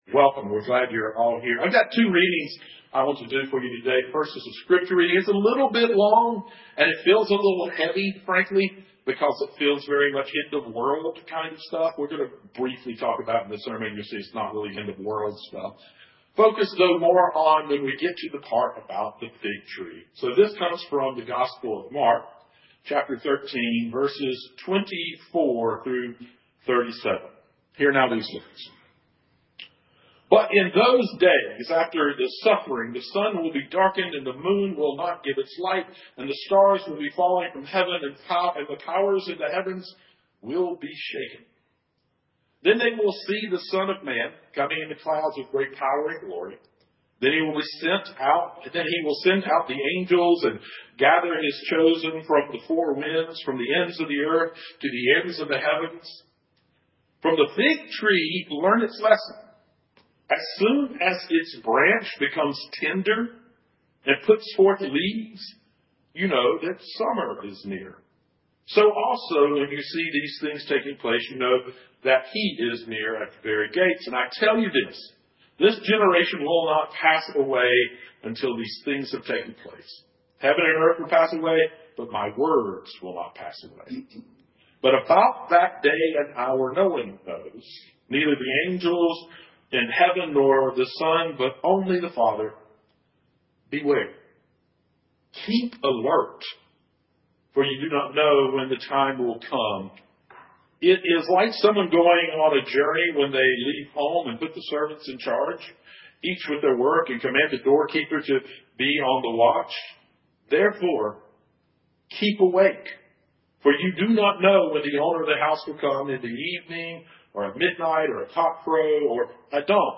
Guest Musician